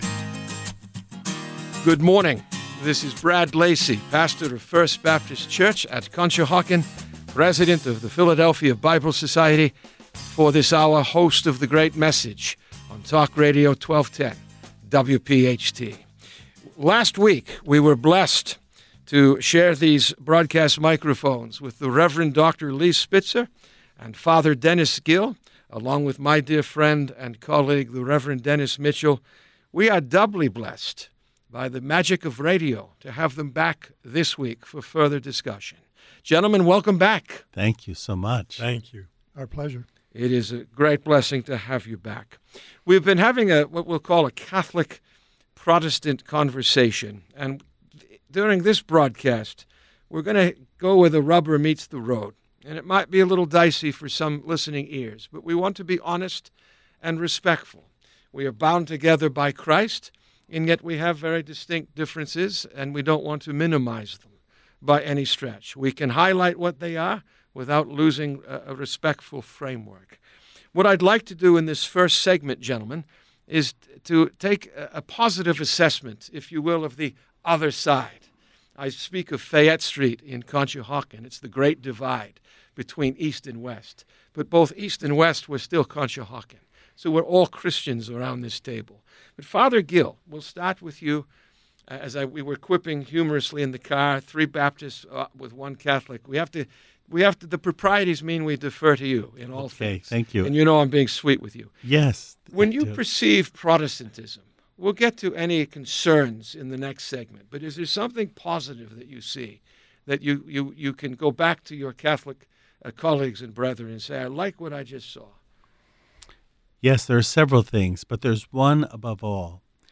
A Catholic-Protestant Conversation